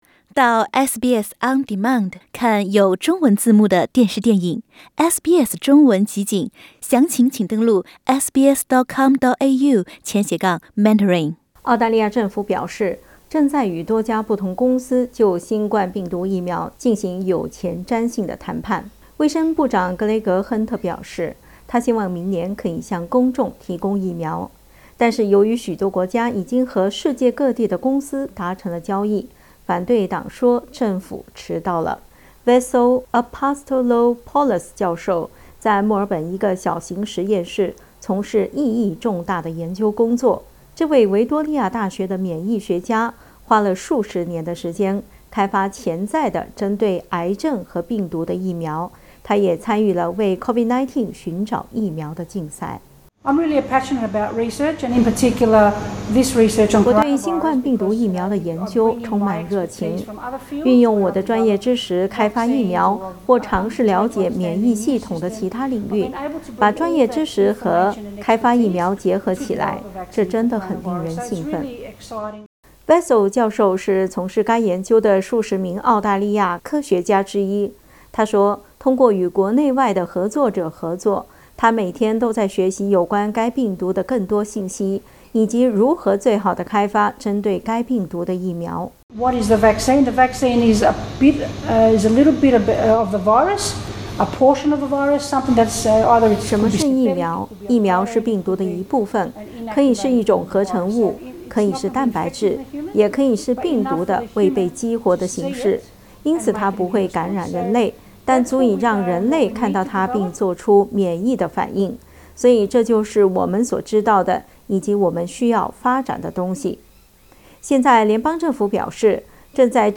但是，由於許多國家已經與世界各地的公司達成交易，反對黨說政府“遲到”了。 點擊圖片收聽詳細報道。